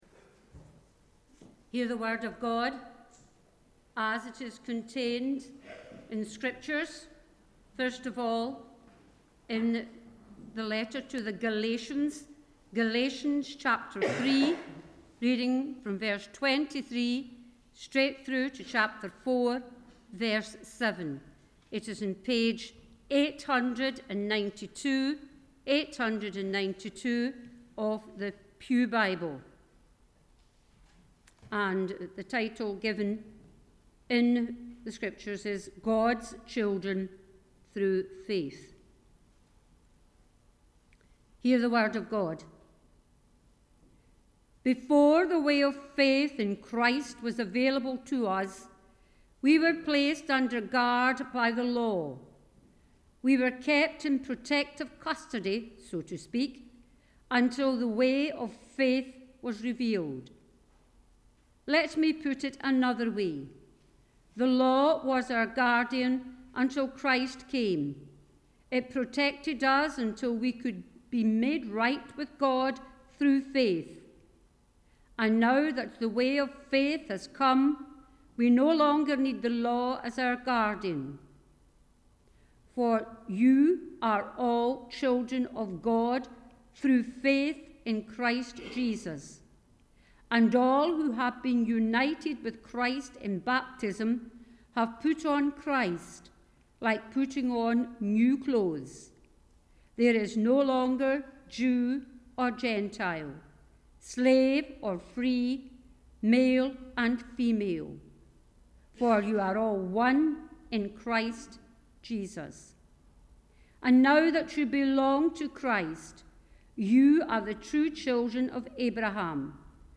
The Scripture Readings prior to the Sermon are Galatians 3:23 – 4:7 & Mark 1: 14-15